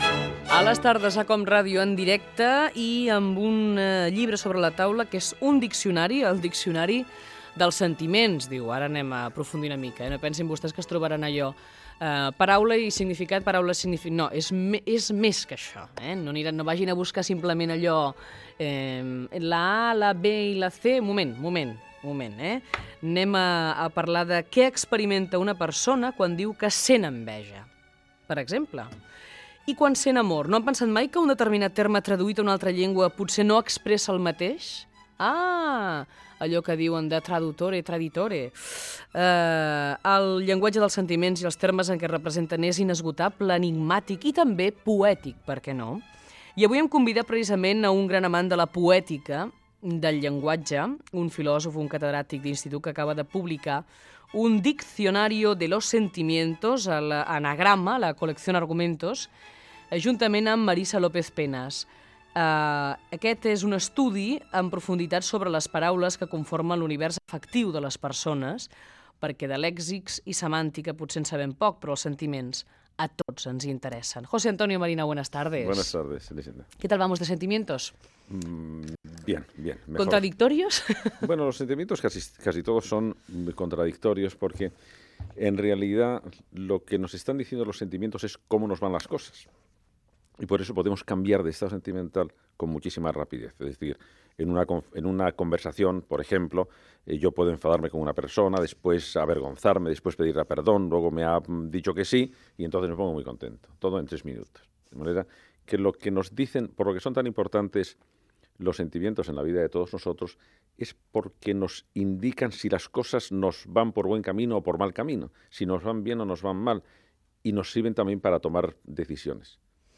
Fragment d'una entrevista al filòsof José Antonio Marina
Fragment extret de l'arxiu sonor de COM Ràdio.